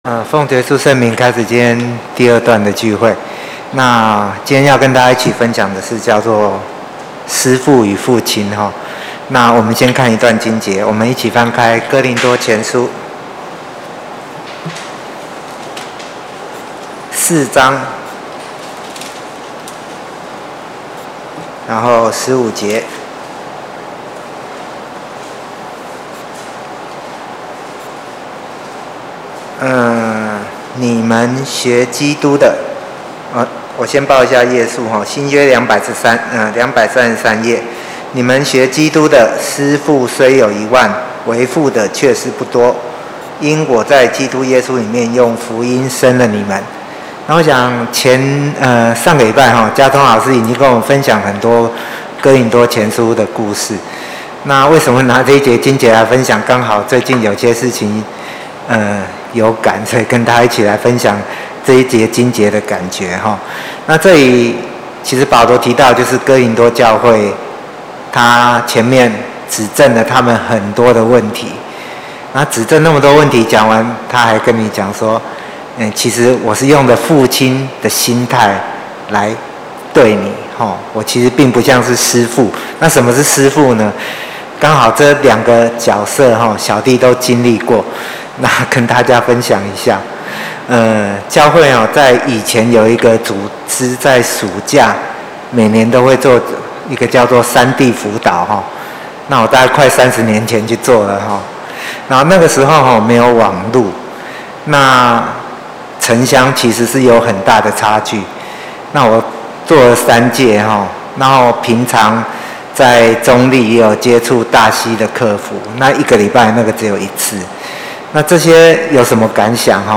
2020年11月份講道錄音已全部上線